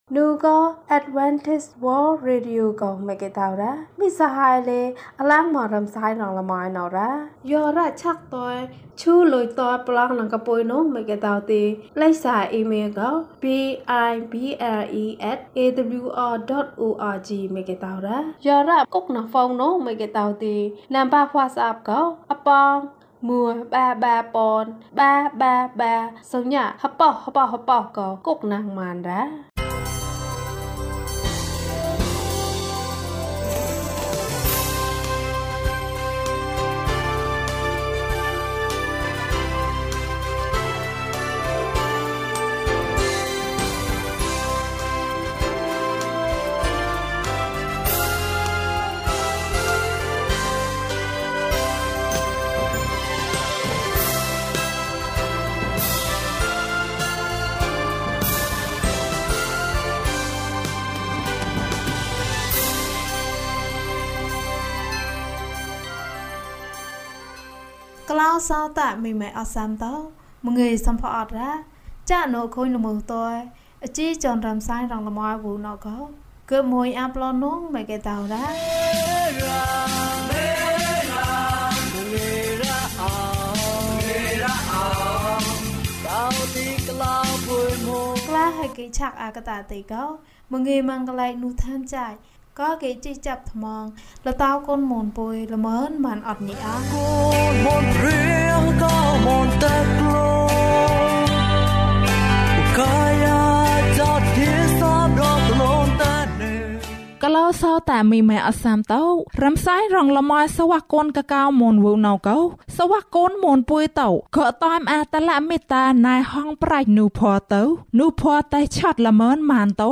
အံ့သြဖွယ်နေ့။ ကျန်းမာခြင်းအကြောင်းအရာ။ ဓမ္မသီချင်း။ တရားဒေသနာ။